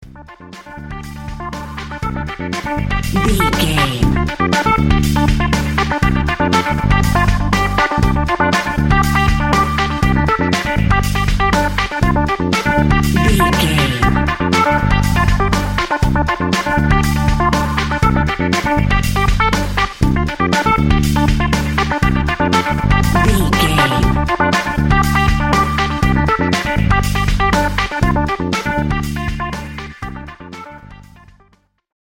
Electric Funk.
Aeolian/Minor
D
techno
trance
glitch
synth lead
synth bass
synth drums